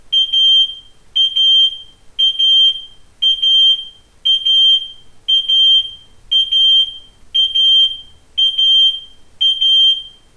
Aus diesem Grund haben wir die Warnmelder Alarme zahlreicher aktueller Modelle für Sie aufgezeichnet.
abus-rwm450-funkrauchmelder-alarm.mp3